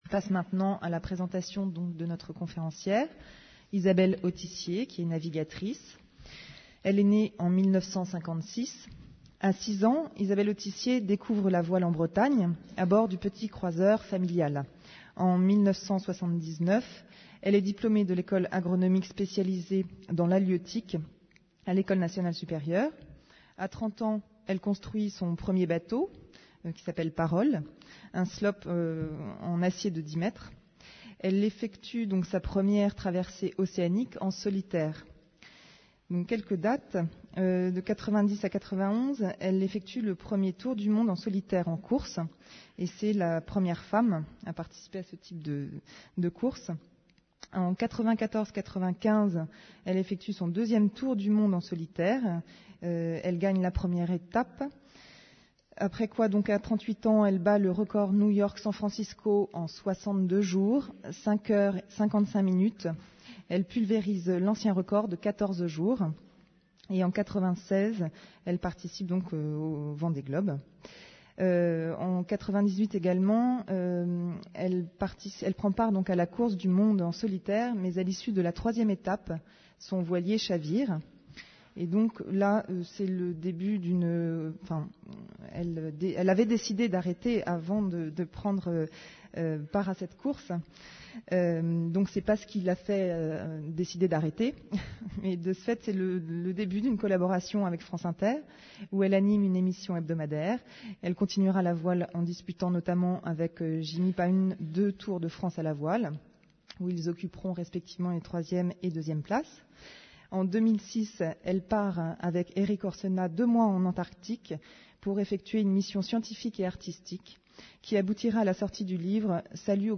Une conférence de l'Université de tous les savoirs par Isabelle AUTISSIER